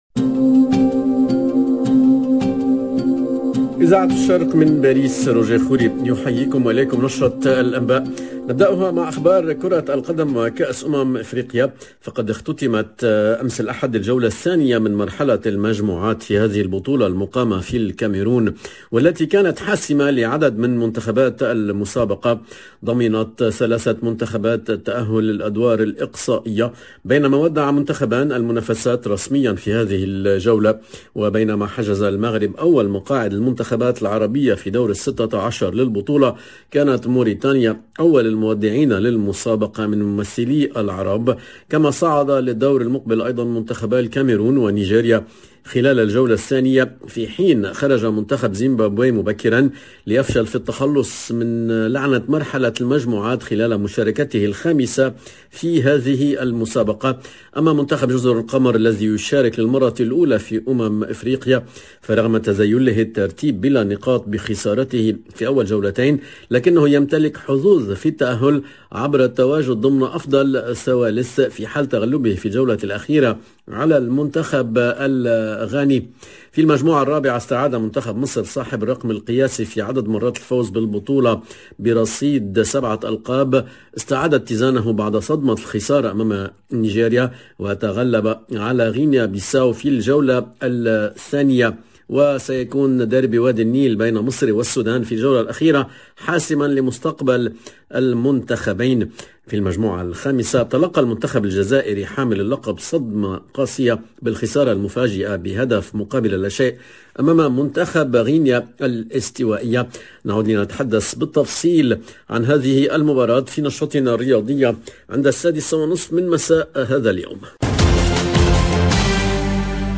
LE JOURNAL DE MIDI 30 EN LANGUE ARABE DU 17/01/22